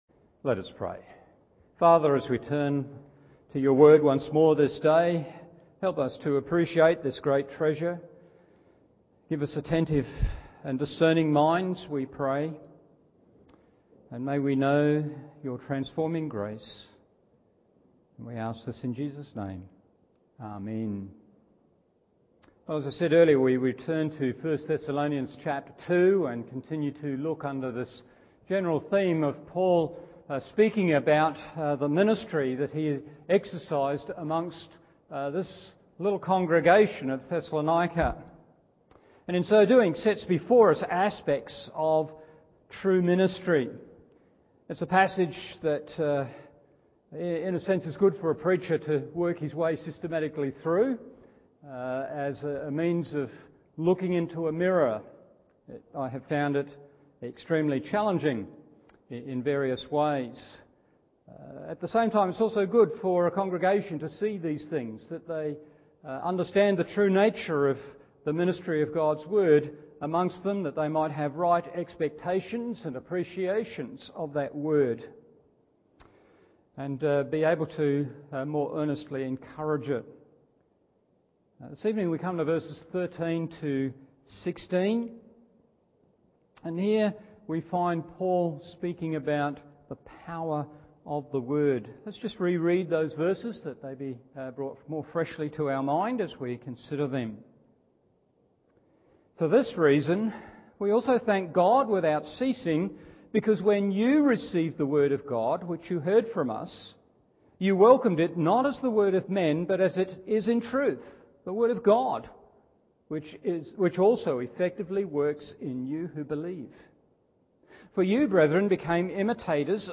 Evening Service 1 Thessalonians 2:13-16 1.